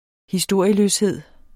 Udtale [ hiˈsdoɐ̯ˀiəløsˌheðˀ ]